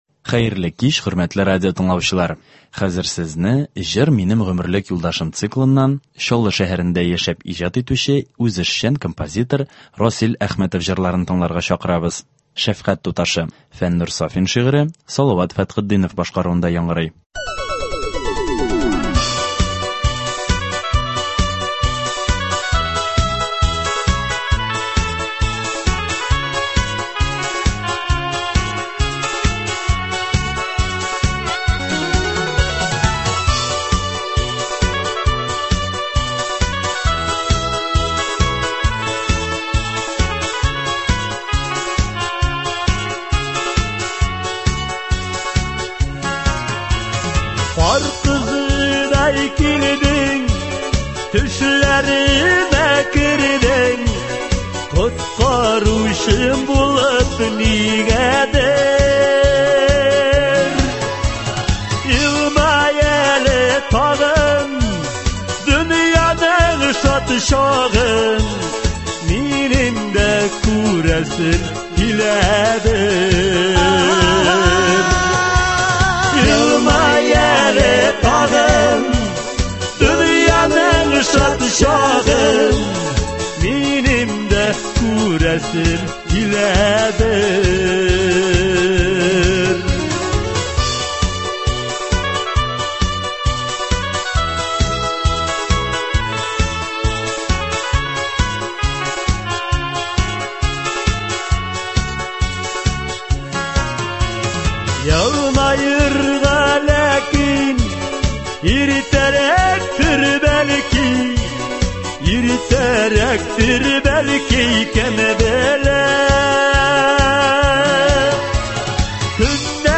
Концерт. 6 июль.